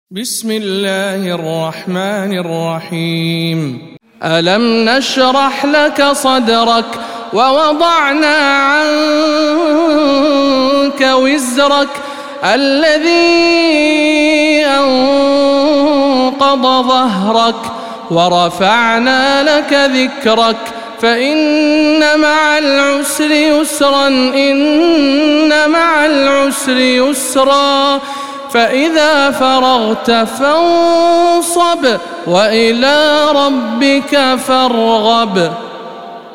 سورة الشرح - رواية ابن ذكوان عن ابن عامر